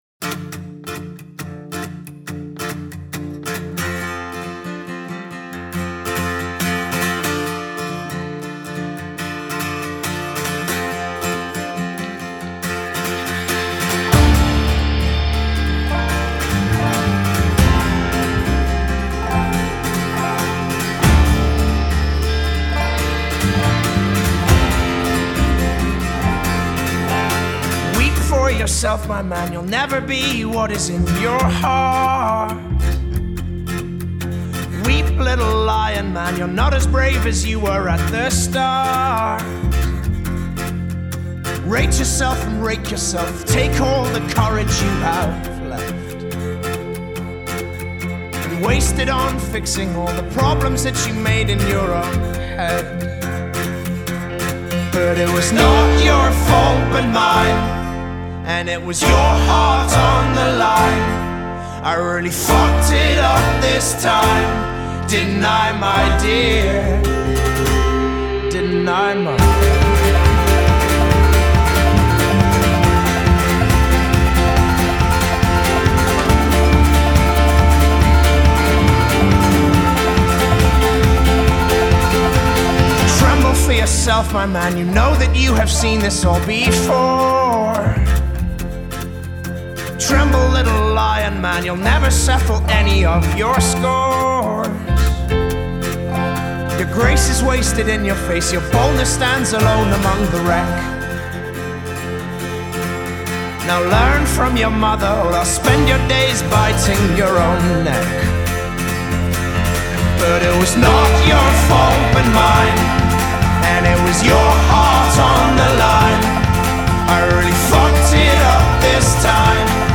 It’s an acoustic delight from nu-folk banjo pluckers
builds through the verse and delivers in the chorus